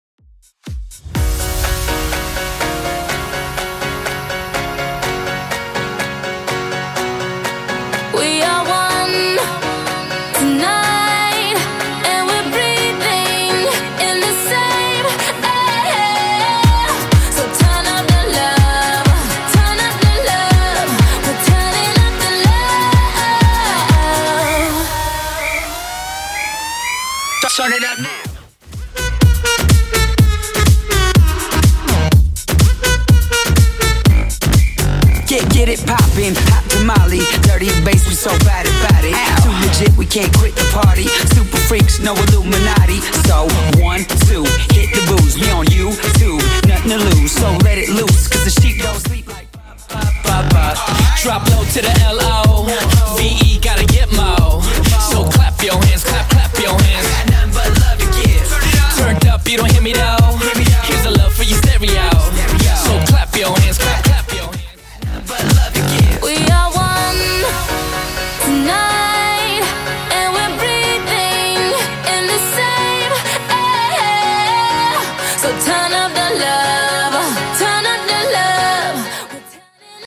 Genre: 70's
BPM: 128